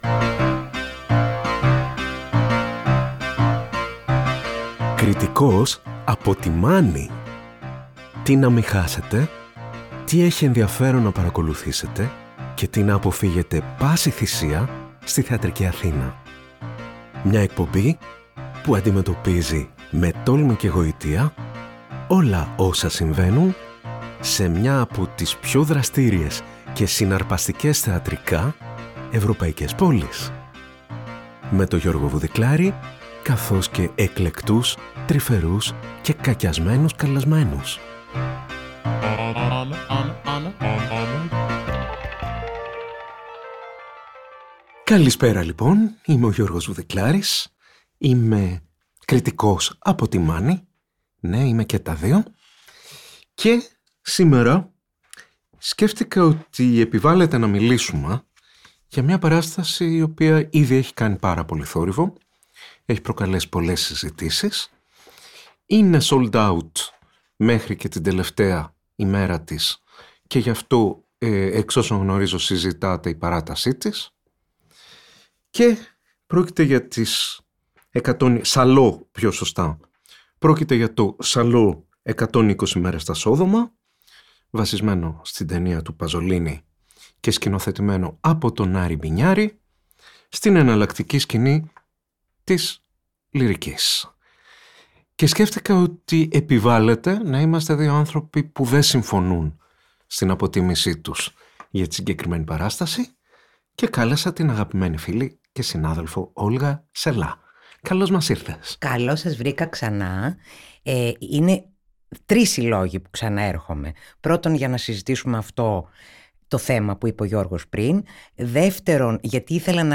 Συζητήσεις μεταξύ κριτικών που συμφωνούν ή διαφωνούν για το τι δεν πρέπει να χάσουμε, αλλά και το τι πρέπει να αποφύγουμε στη θεατρική Αθήνα.